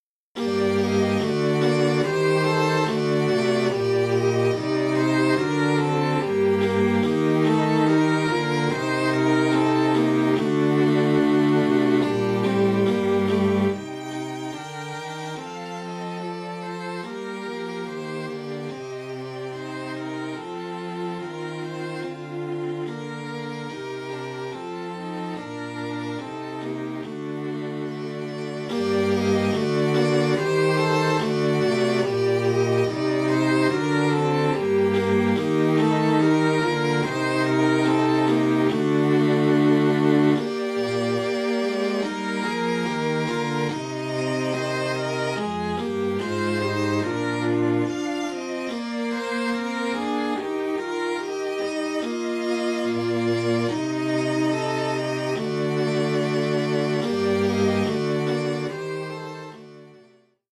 Formule instrumentale : Quatuor à cordes
Oeuvre pour quatuor à cordes.
Violon 1, violon 2, alto, violoncelle.